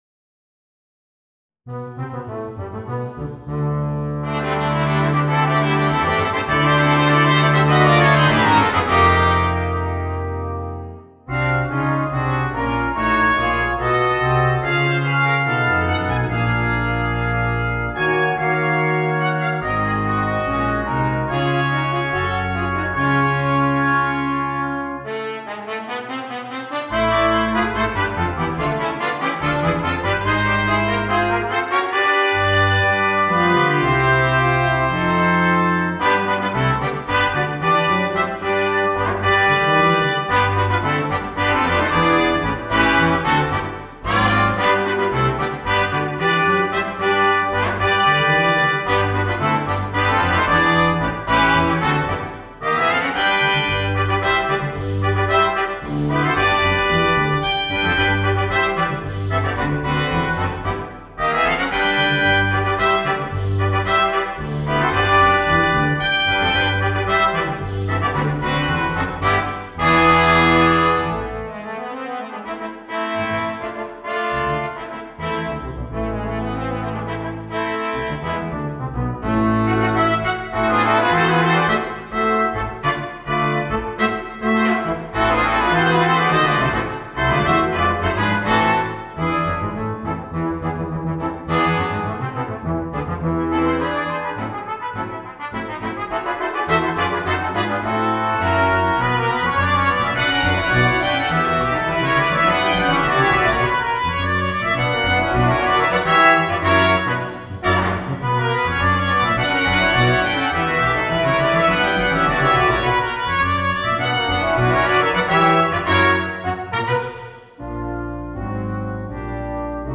Voicing: 2 Trumpets, Horn, Trombone, Tuba and Drums